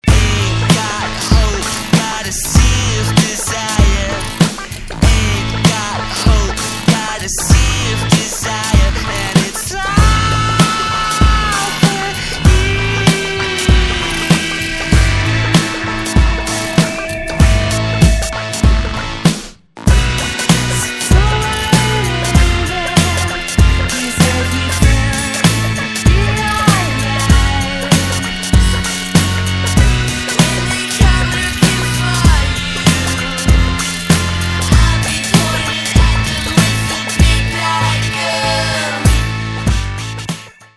néo-psychédéliques